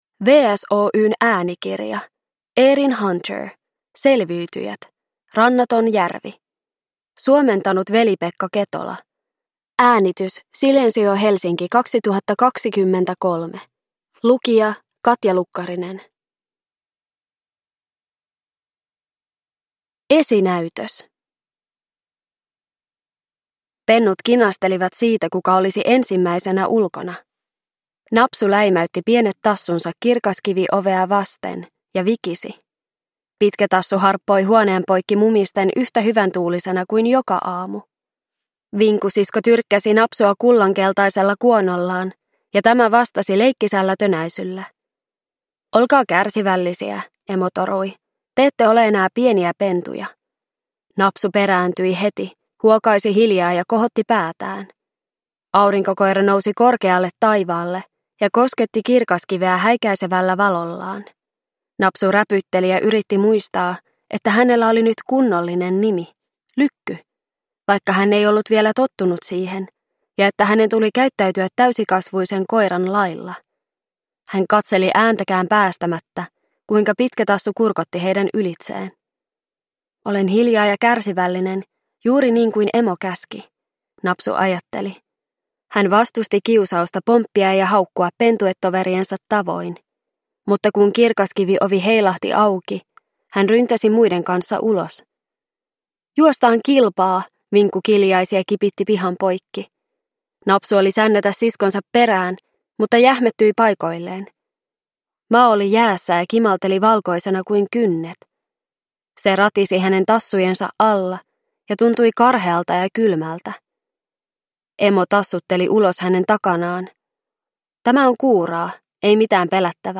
Selviytyjät: Rannaton järvi – Ljudbok